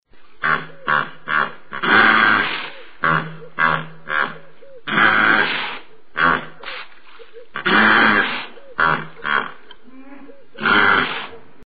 Погрузитесь в мир звуков бегемотов – от громкого рева до забавного фырканья!
Так кричит бегемот